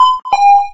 Chime1.ogg